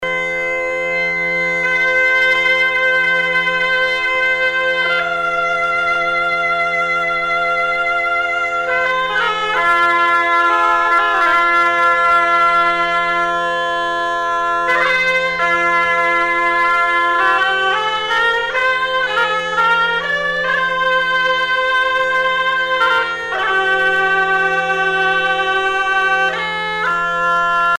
Musique traditionnelle bretonne